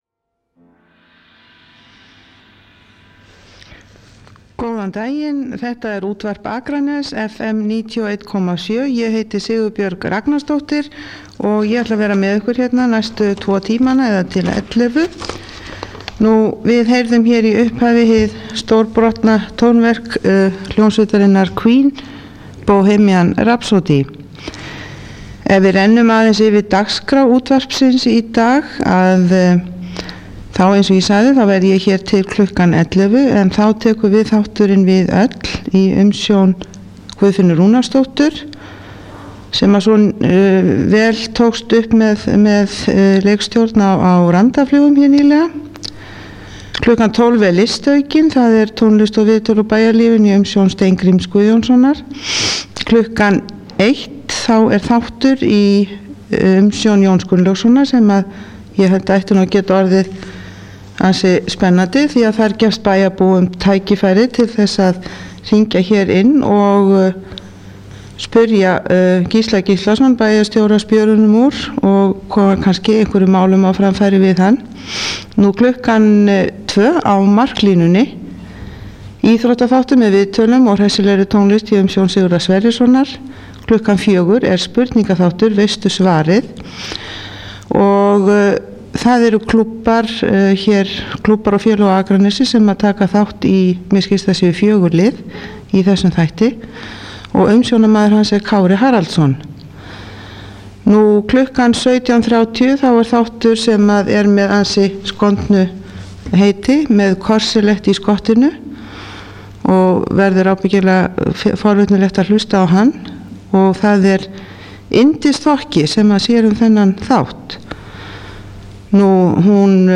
Blandaður morgunþáttur með viðtölum, dagskrárkynningu og tónlist.